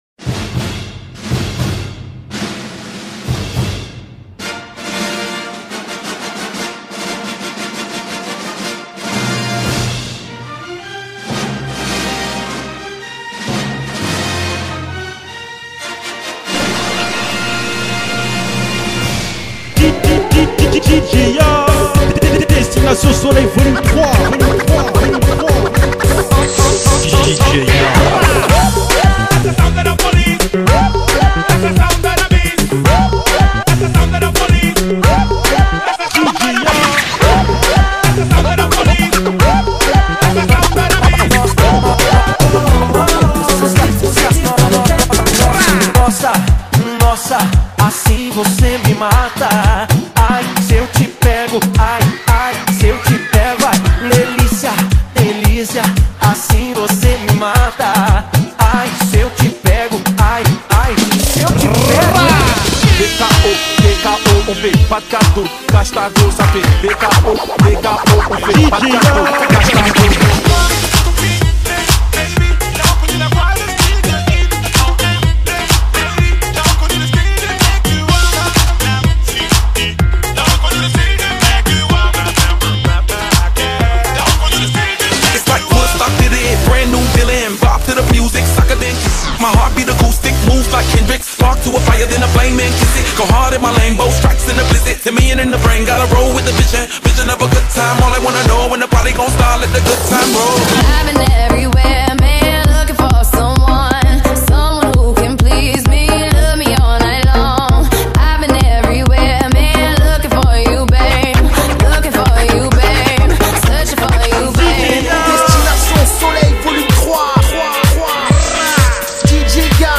Je suis un dj généraliste qui s'adapte à tous les styles : house, r'nb,Rai, fun